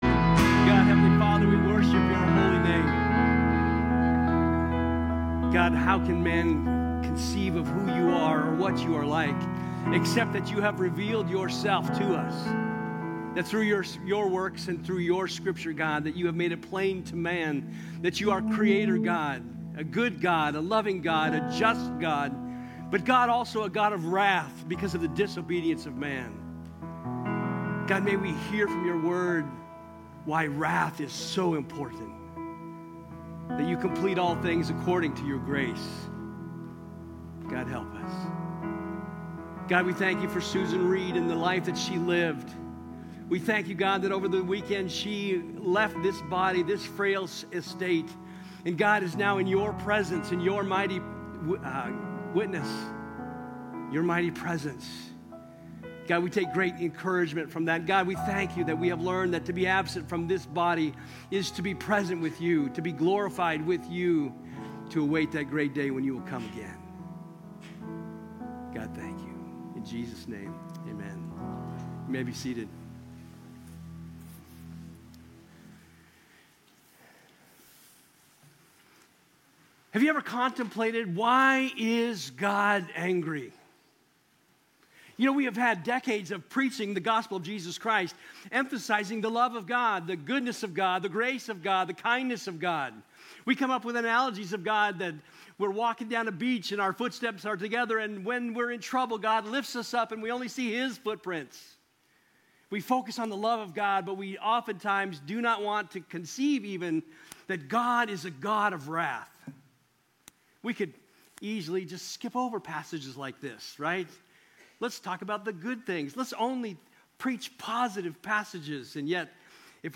Sermon Archive | Avondale Bible Church